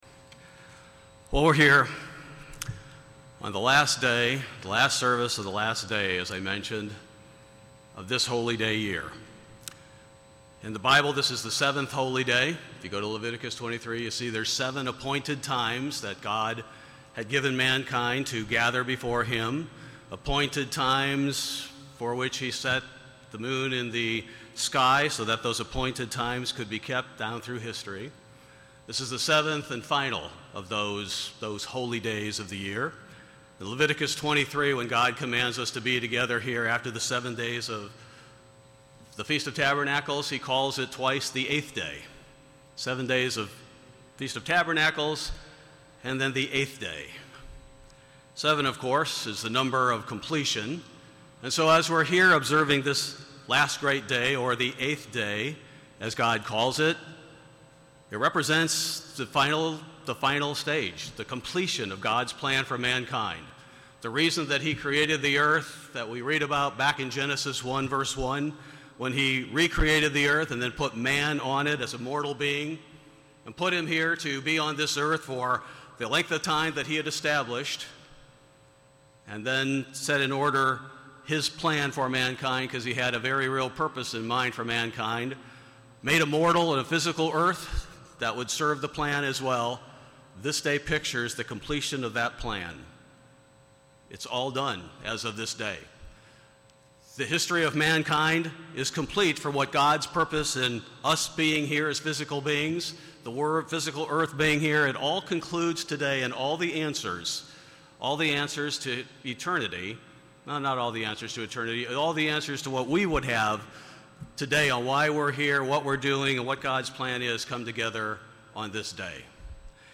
This sermon was given at the Daytona Beach, Florida 2021 Feast site.